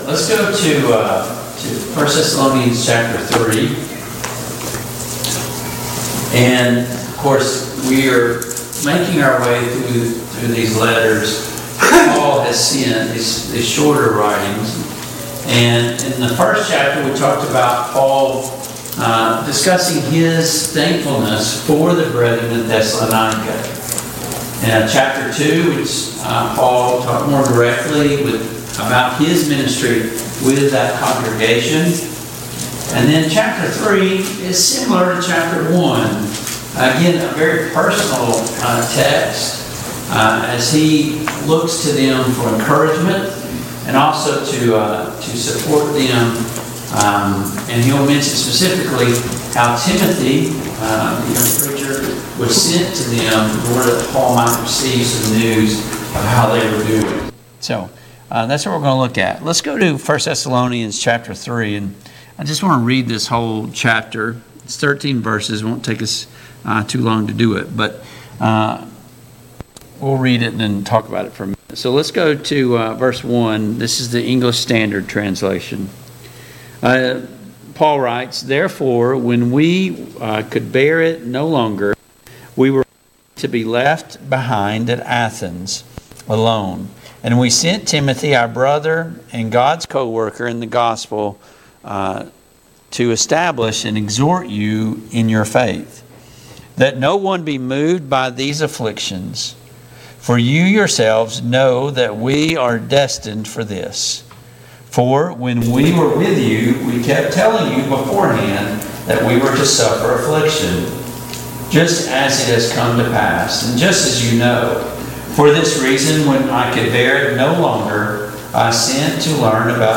Passage: 1 Thessalonians 3:1-13, 1 Thessalonians 4:1-8 Service Type: Mid-Week Bible Study